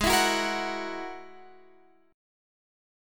Listen to G#7sus2#5 strummed